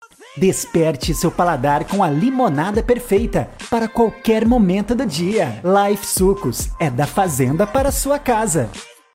Animada